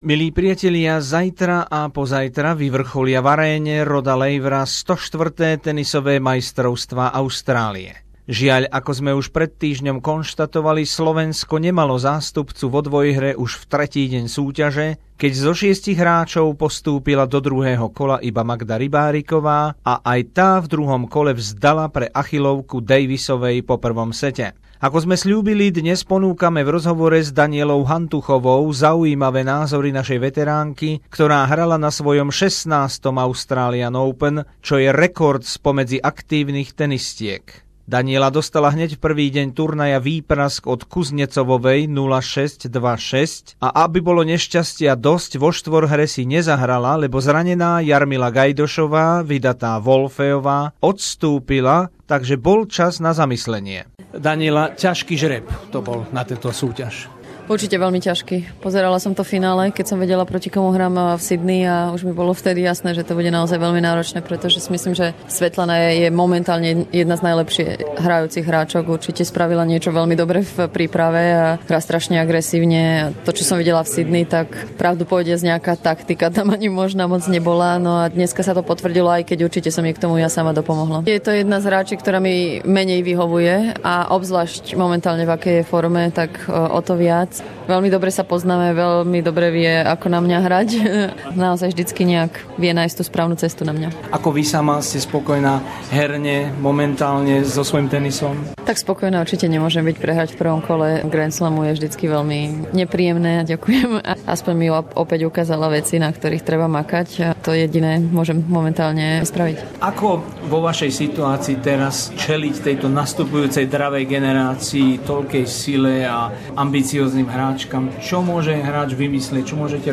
Rozhovory z tenisového Australian Open - druhý týždeň